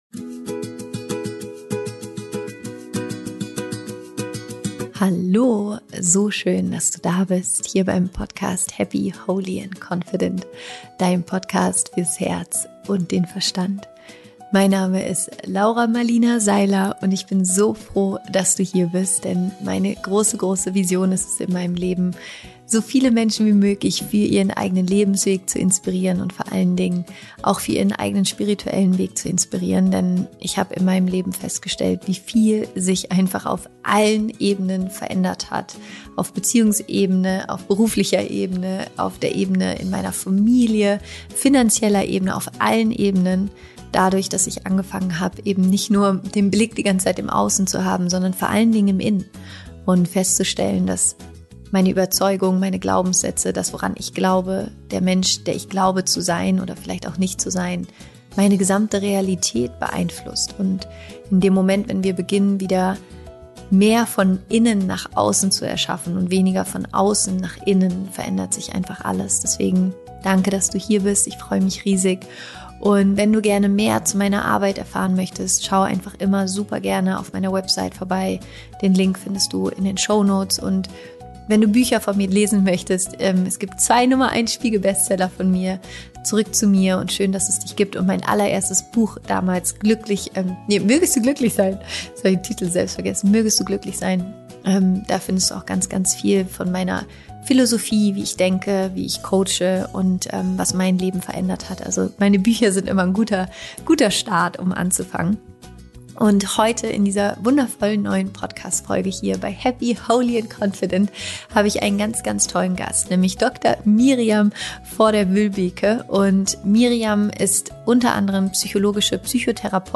Im Interview sprechen wir darüber, was Gefühle sind und wie du sie voll und ganz zulässt.